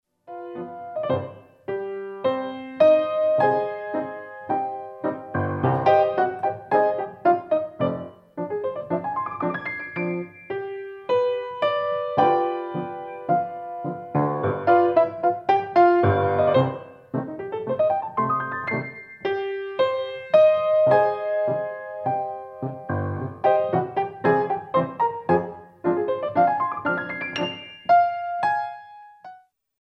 SLOW